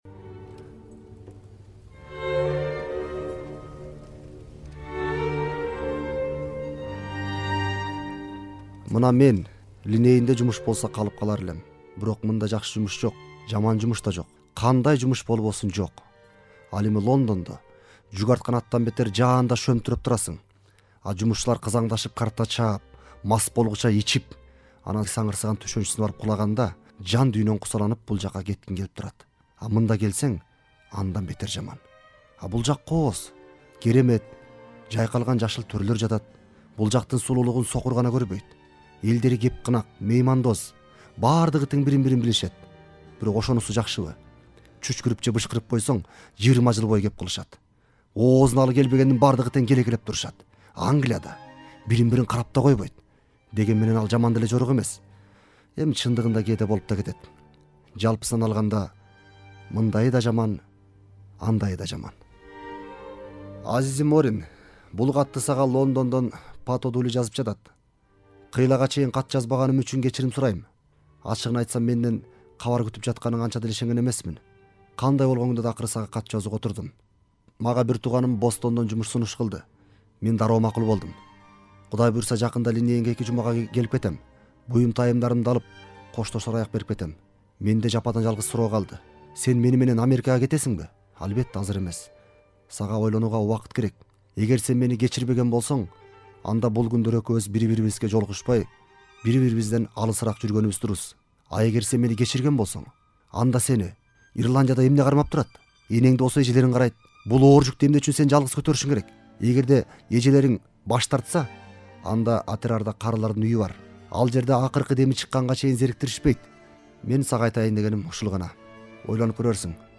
Спектаклден үзүндү